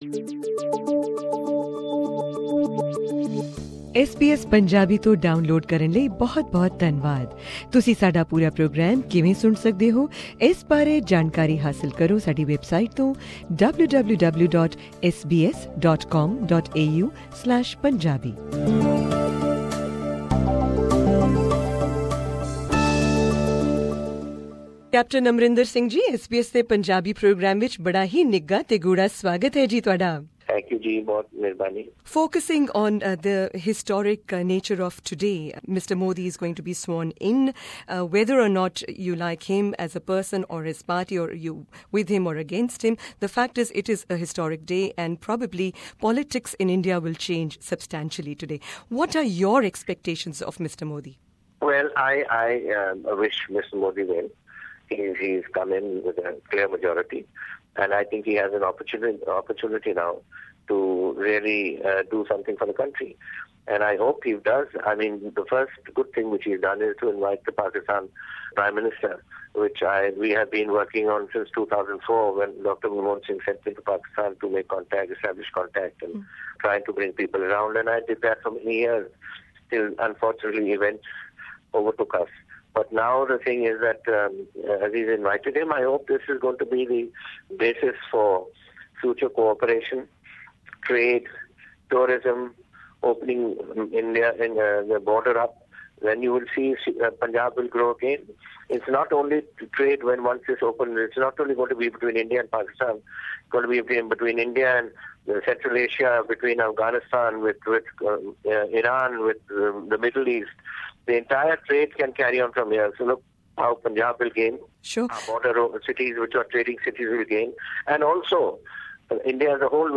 Interview with Capt Amarinder Singh, Punjab Congress Chief